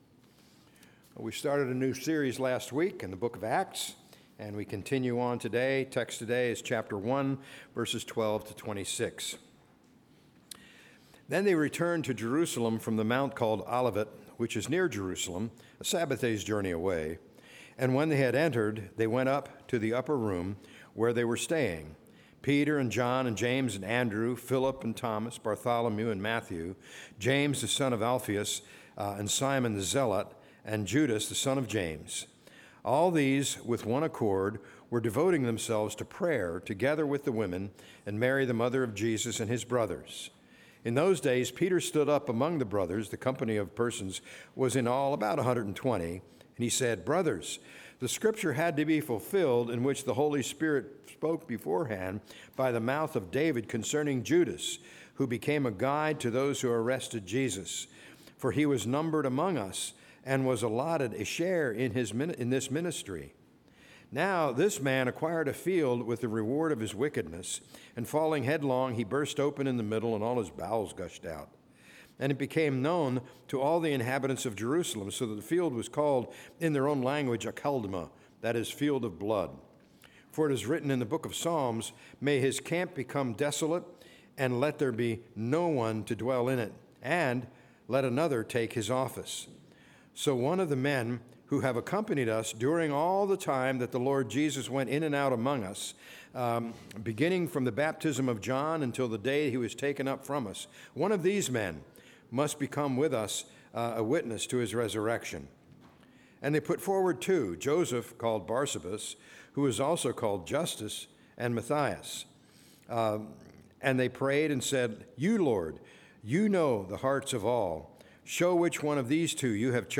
A message from the series "Act 2025."